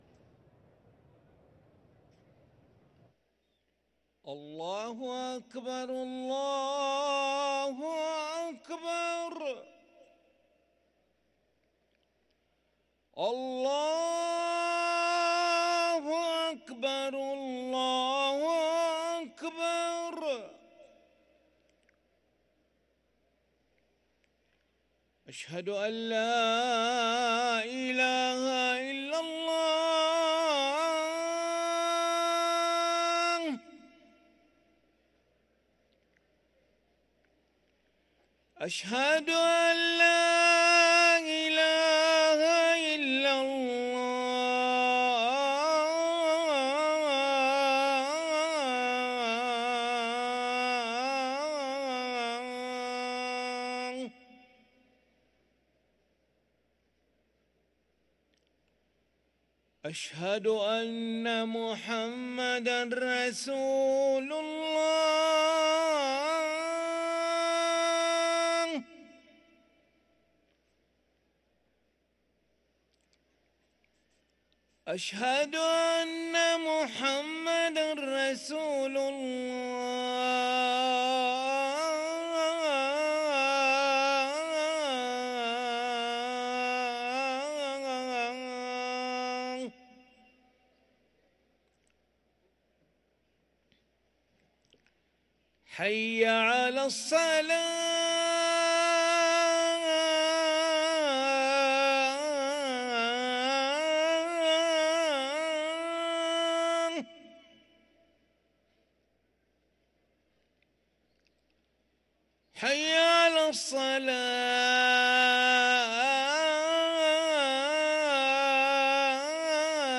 أذان العشاء للمؤذن علي ملا الخميس 18 رجب 1444هـ > ١٤٤٤ 🕋 > ركن الأذان 🕋 > المزيد - تلاوات الحرمين